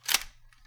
sfx_reload_2.mp3